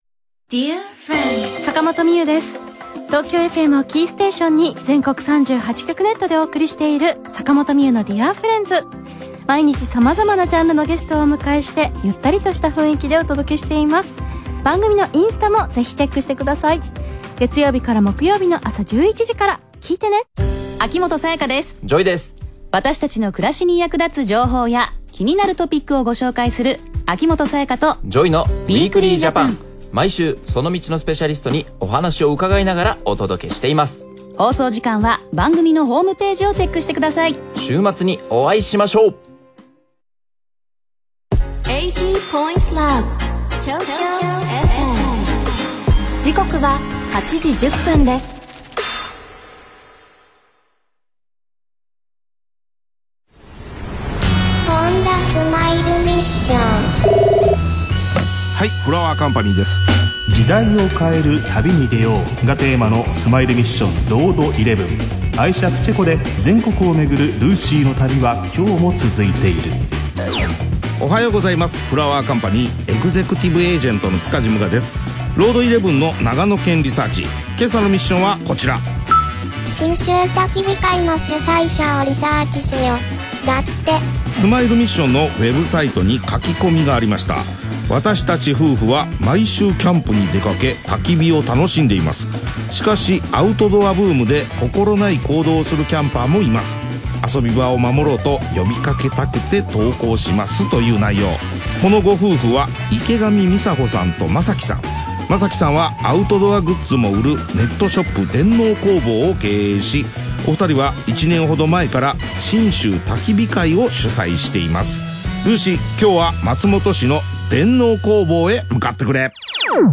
今日オンエアーのTOKYO FM (FM長野)の番組、Honda Smile Missionで、信州焚火会のことを取り上げていただきました。